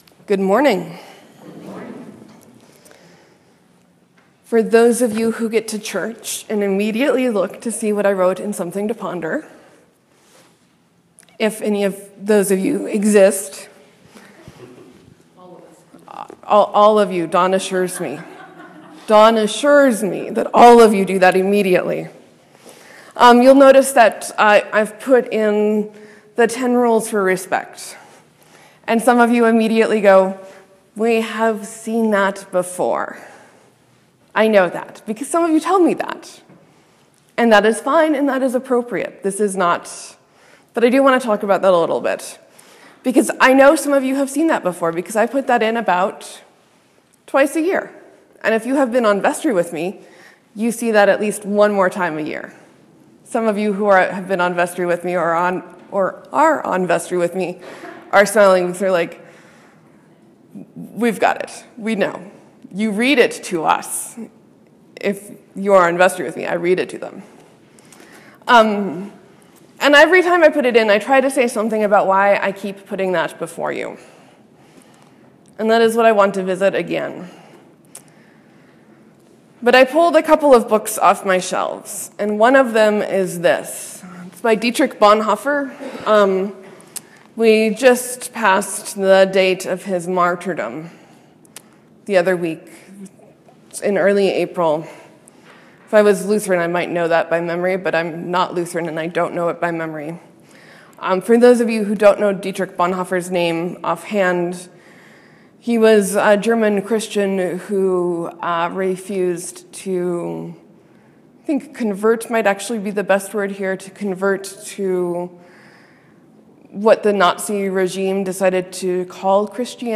Sermon: Jesus looks at his recently deserting, scared and lost disciples and calls them his witnesses.